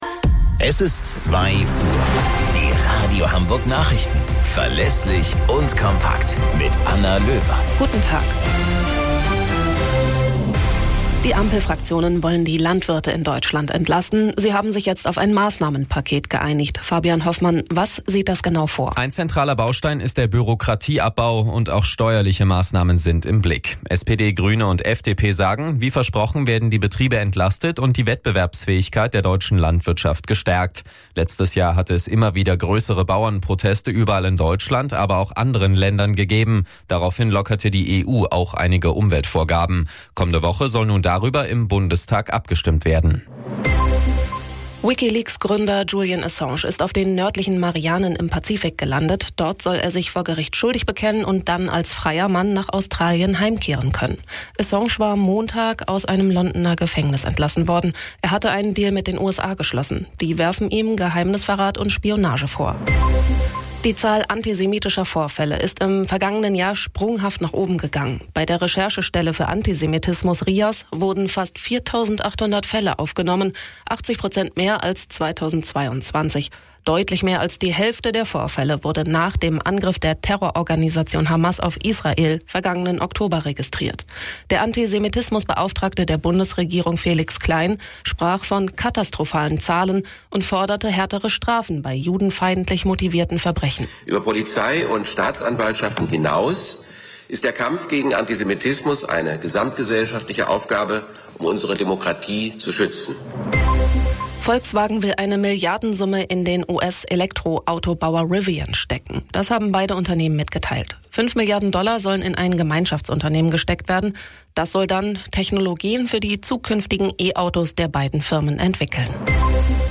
Radio Hamburg Nachrichten vom 26.06.2024 um 08 Uhr - 26.06.2024